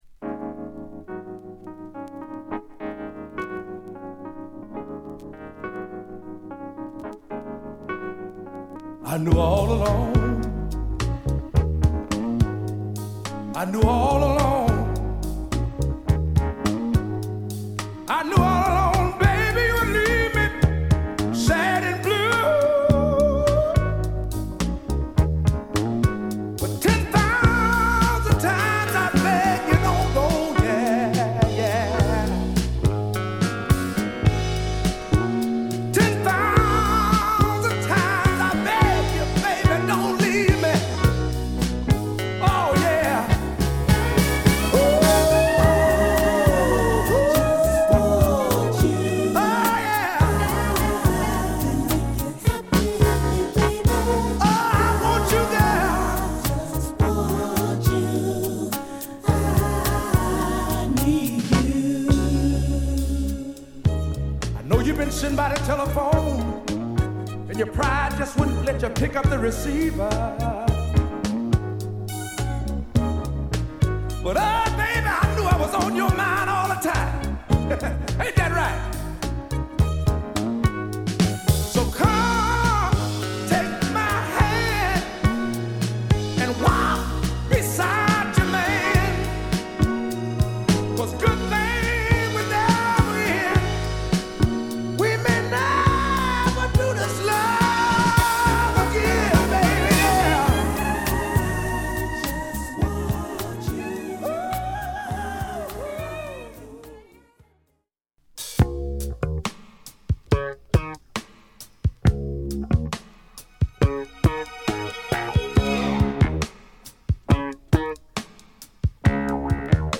この時代らしいモダンな仕上がりの1枚。...